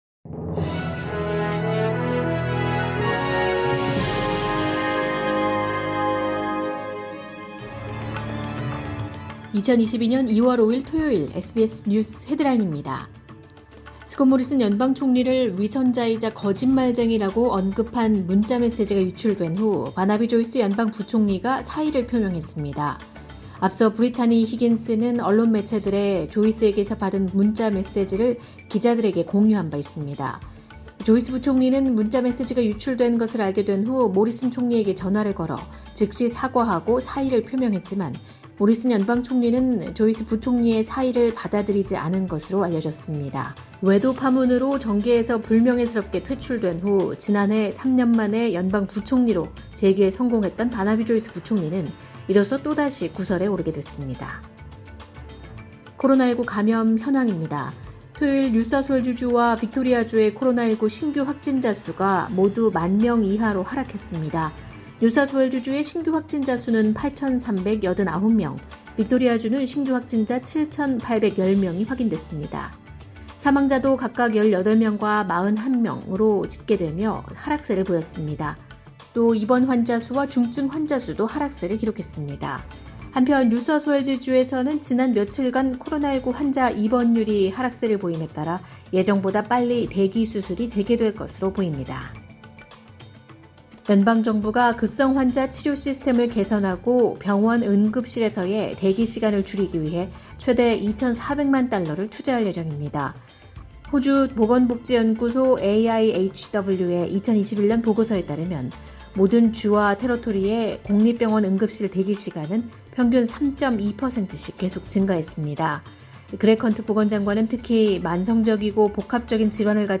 2022년 2월 5일 토요일 SBS 뉴스 헤드라인입니다.